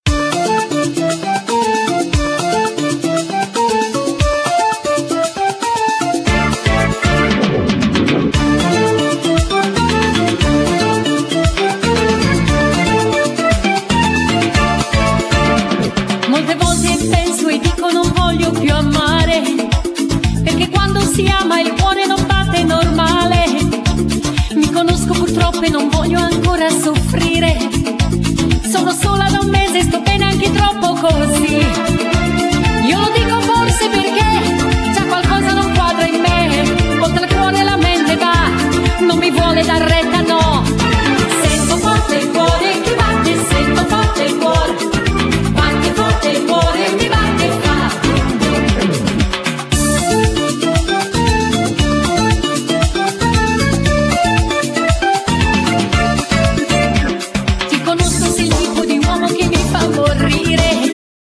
Genere : Liscio